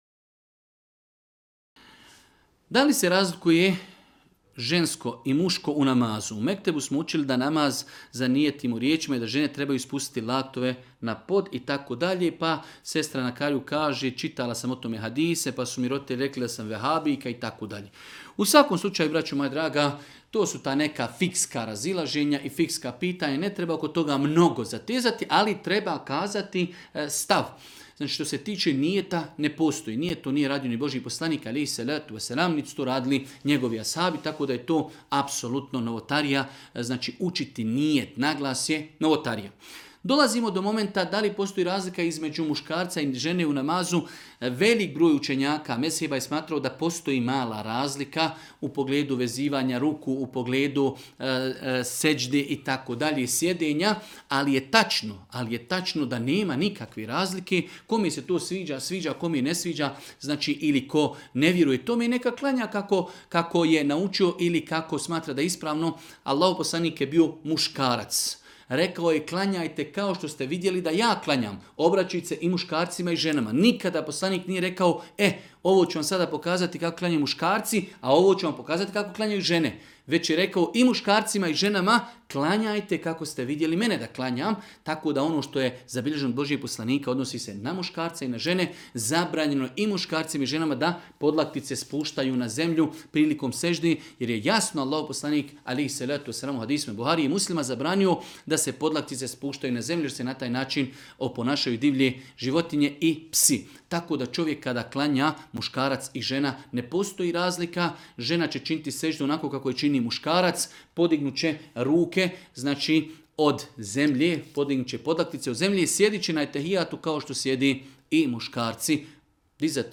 video predavanju